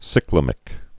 (sĭklə-mĭk, sīklə-)